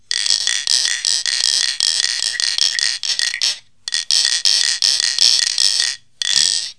4.2.2.1.CẶP KÈ hay SÊNH SỨA
Rè: Ví dụ: (470-3a)